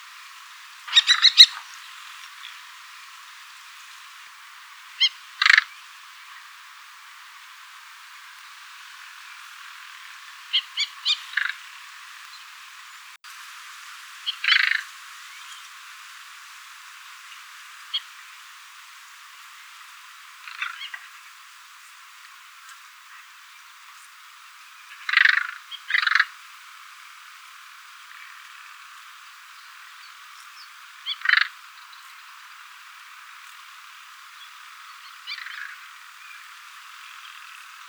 FALCO TINNUNCULUS - KESTREL - GHEPPIO
- POSITION: Poderone near Magliano in Toscana, LAT.N 42°36'/LONG.E 11°17'- ALTITUDE: +130 m. - VOCALIZATION TYPE: threat calls in flight.
- COMMENT: The two birds engage in a prolonged aerial fight, characterized by acrobatic flight pursuits and vocal duels. Note the typical rattling call of the Hooded Crow, specifically used for mobbing raptors.